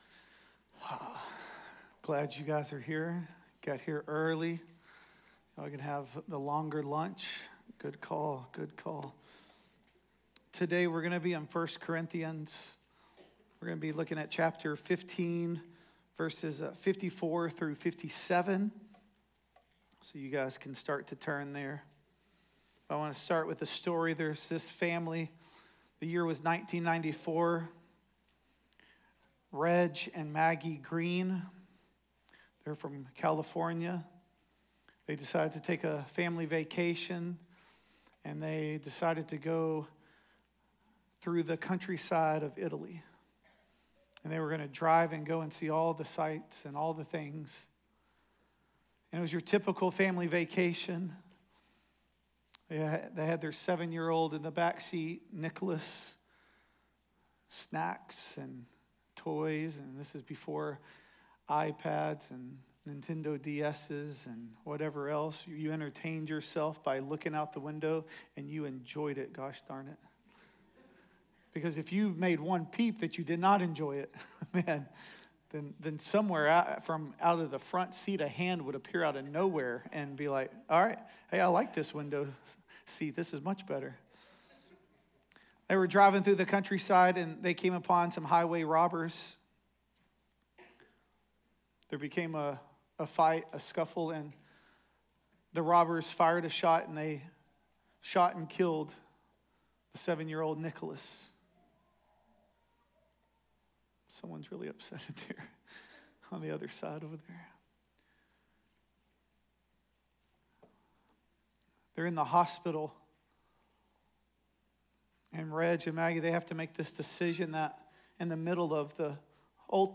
The Gathering at Adell Audio Sermons